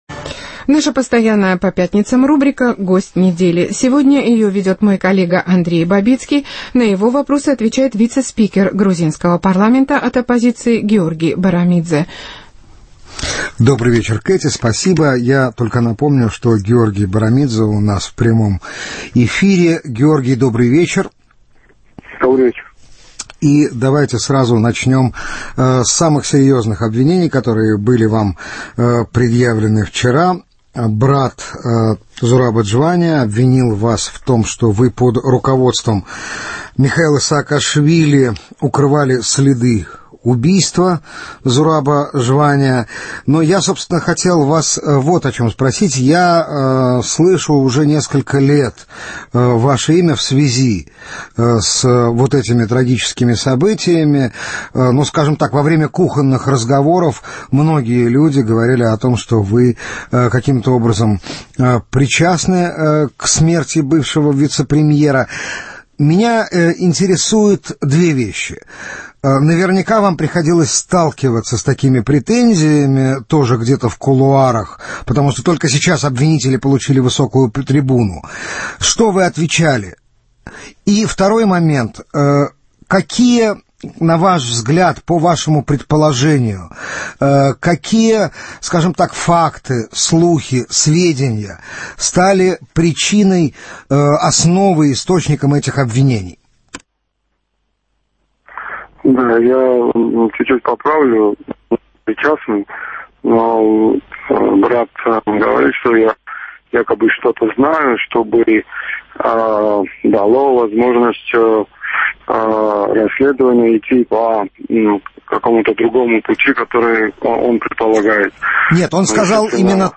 Наша постоянная по пятницам рубрика «Гость недели». Сегодня ее ведет Андрей Бабицкий, на его вопросы отвечает вице-спикер грузинского парламента от оппозиции Георгий Барамидзе.